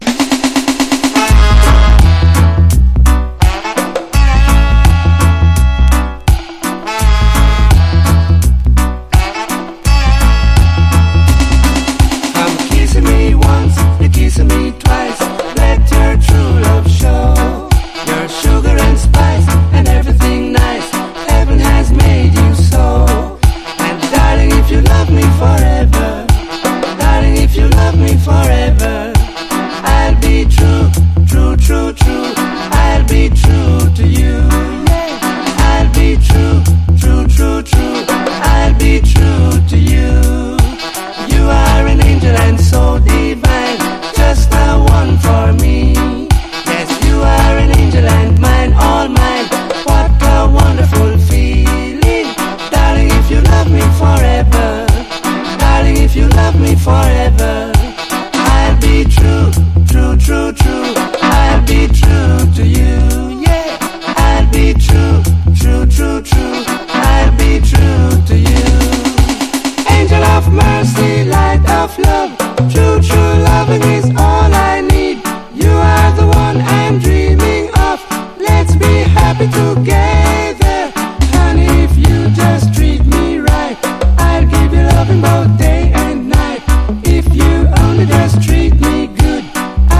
# LOVERS# SKA / ROCK STEADY